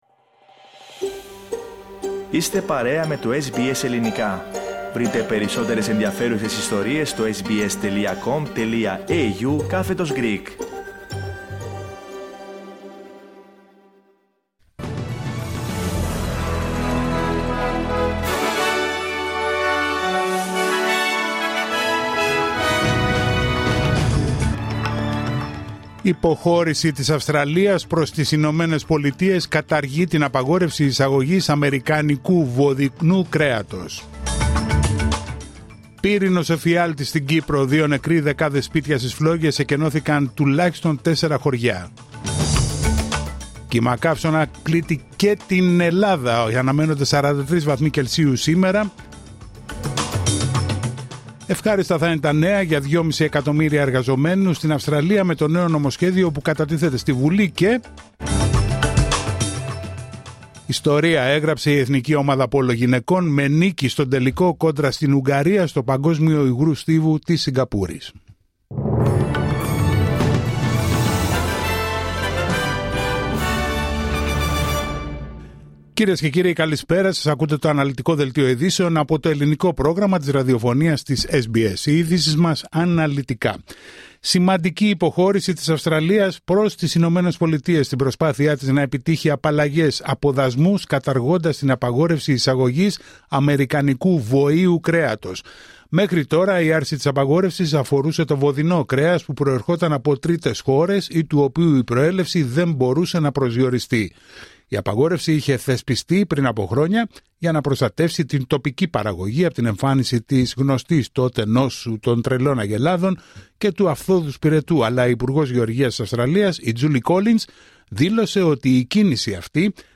Δελτίο ειδήσεων Πέμπτη 24 Ιουλίου 2025